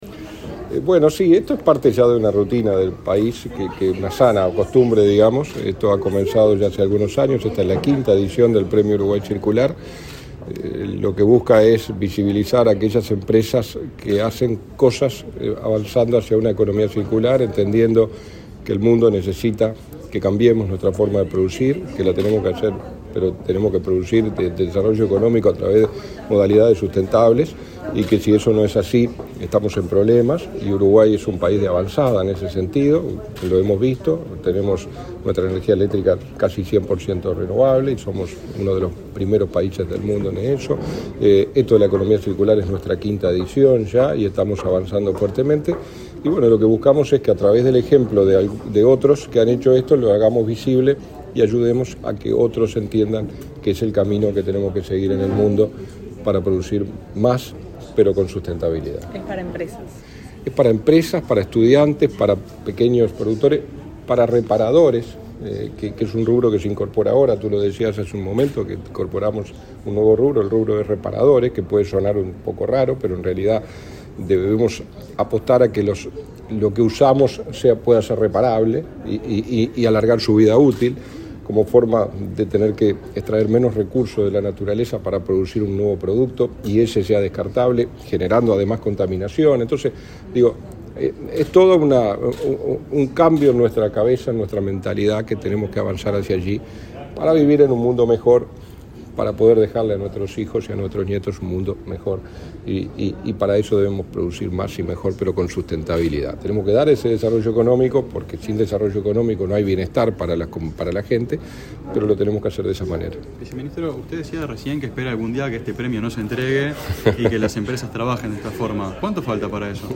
Declaraciones del subsecretario de Industria, Walter Verri
Luego dialogó con la prensa.